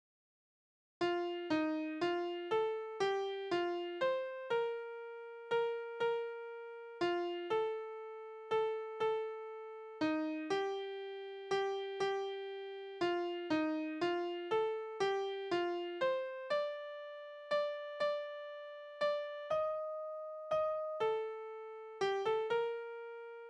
Tonart: B-Dur
Taktart: 3/4
Tonumfang: Oktave
Besetzung: vokal